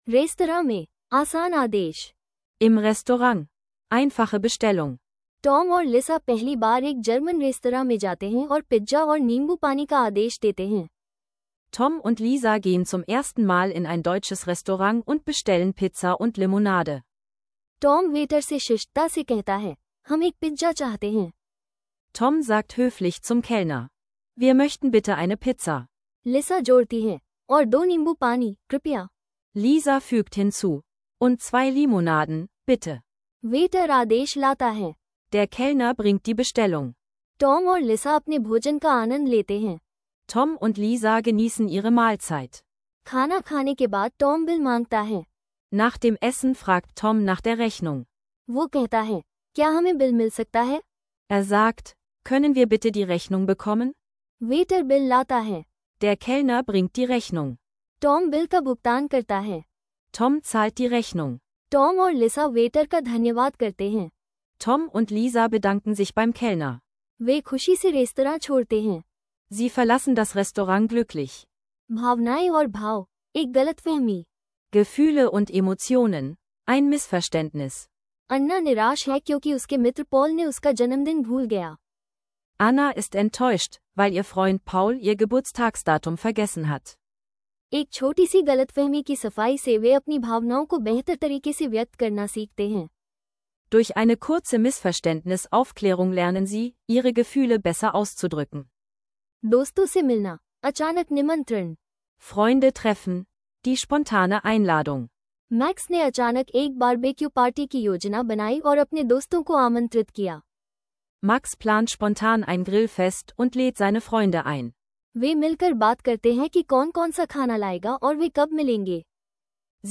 Einfache Hindi-Dialoge für den Restaurantbesuch – Perfekt für Einsteiger und zur Auffrischung!